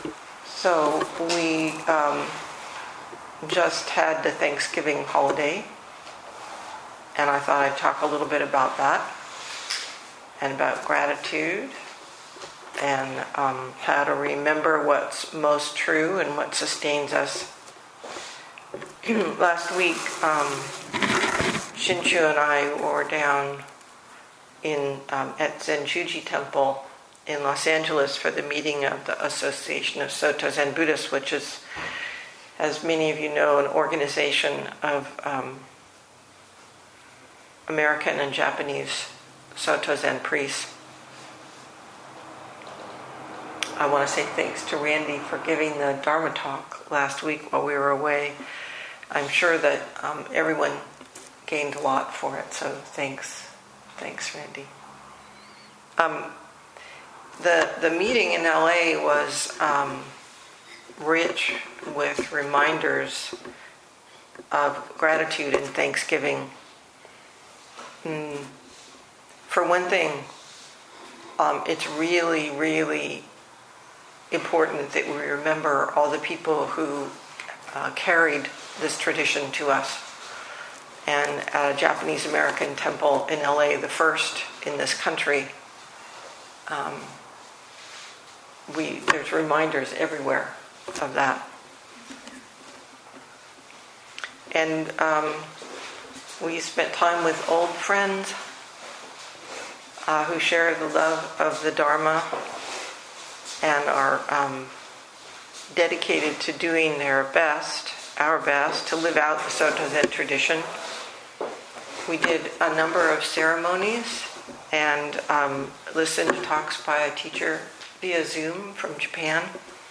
2021 in Dharma Talks